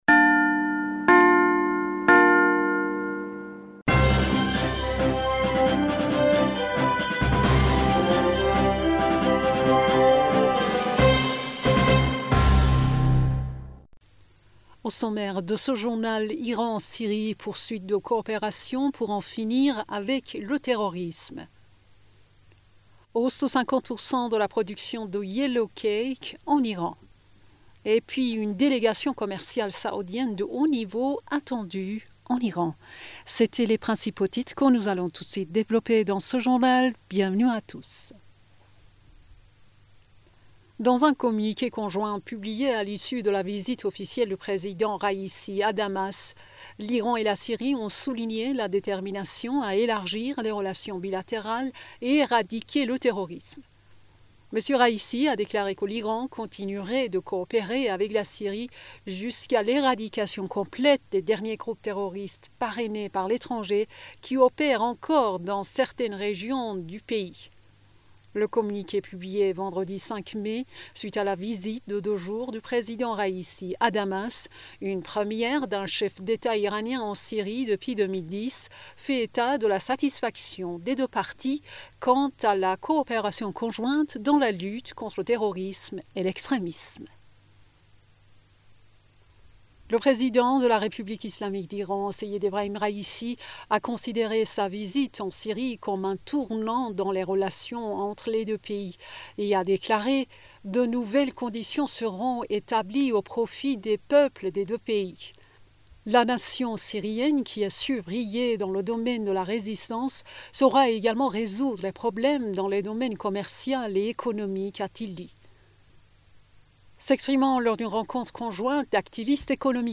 Bulletin d'information du 05 Mai 2023